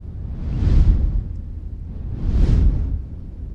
DragonStartingToFly.wav